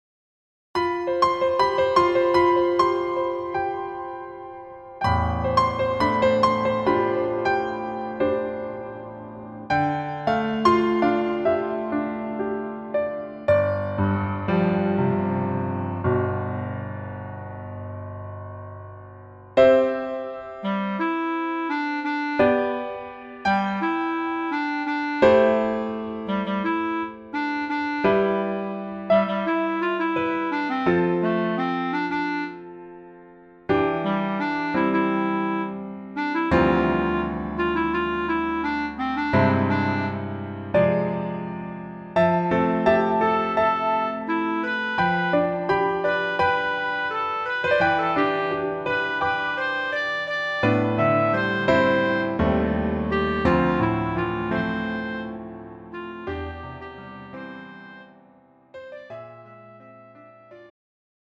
음정 여자키 3:49
장르 가요 구분 Pro MR